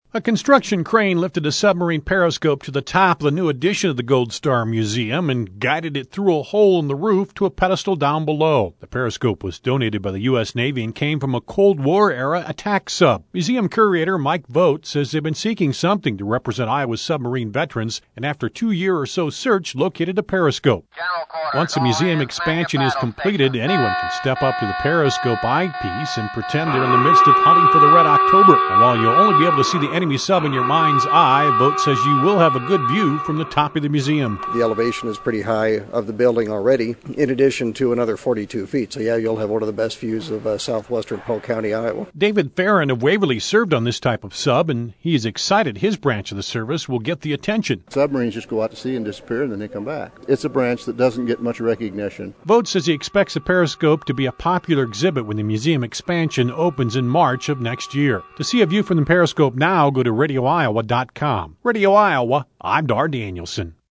reports on the Gold Star Museum periscope